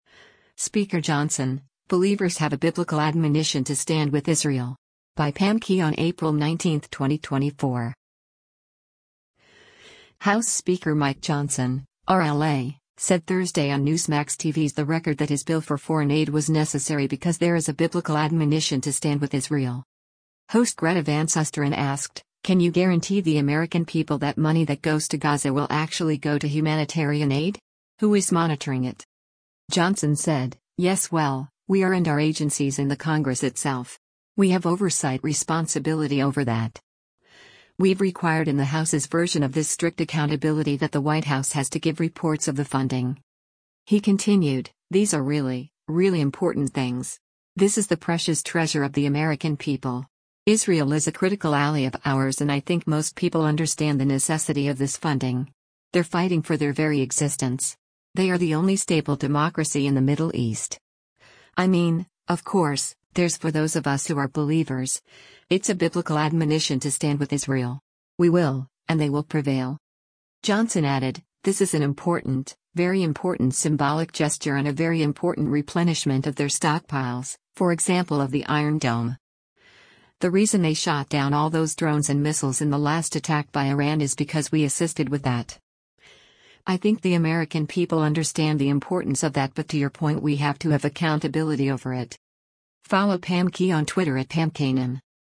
House Speaker Mike Johnson (R-LA) said Thursday on Newsmax TV’s “The Record” that his bill for foreign aid was necessary because there is a “Biblical admonition to stand with Israel.”
Host Greta Van Susteren asked, “Can you guarantee the American people that money that goes to Gaza will actually go to humanitarian aid? Who is monitoring it?”